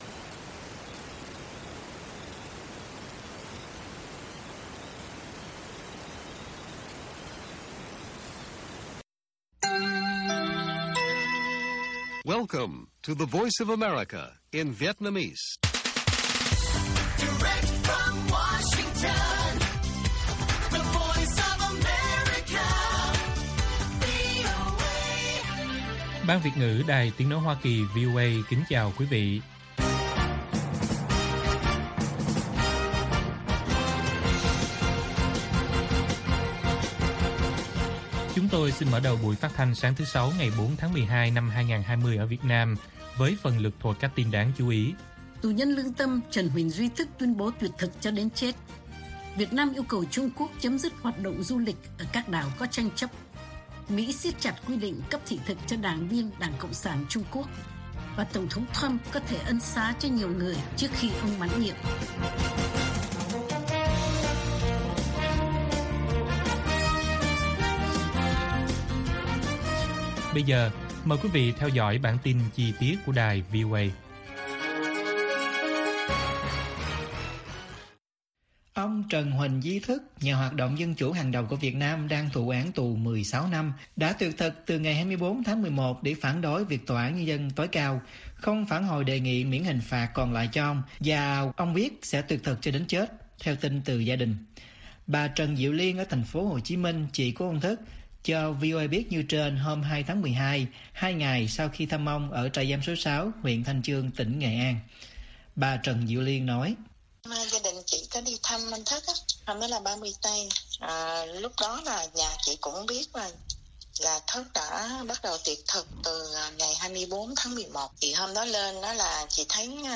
Bản tin VOA ngày 4/12/2020